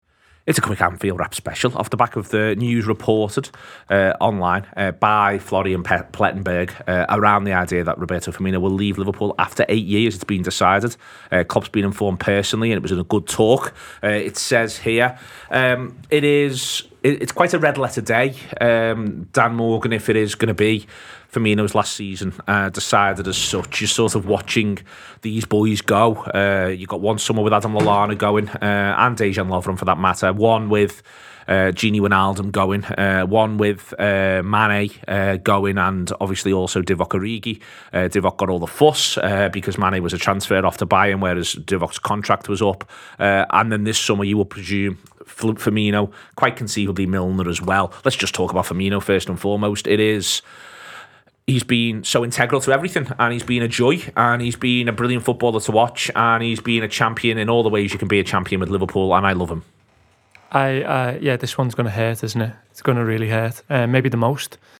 Below is a clip from the show – subscribe for more on reports Roberto Firmino is set to leave Liverpool…